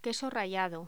Locución: Queso rallado
voz
Sonidos: Voz humana